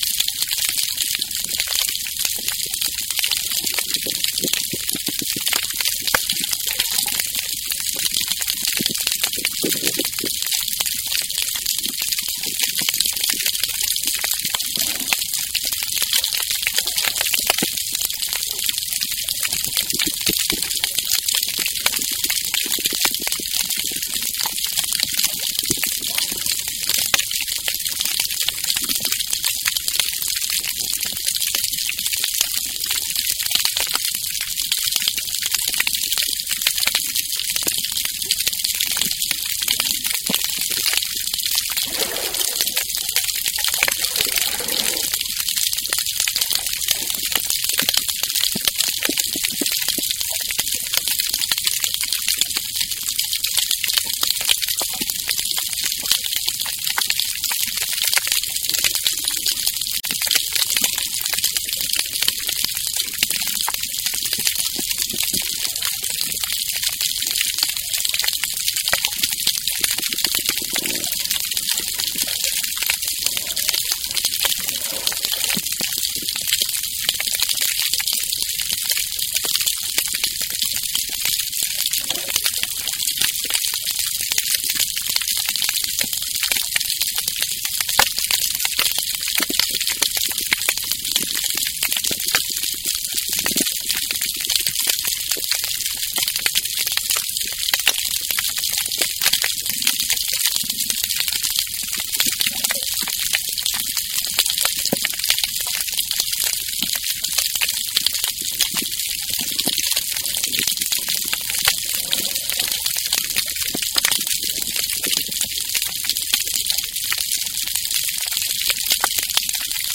Demonstration soundscapes
geophony
biophony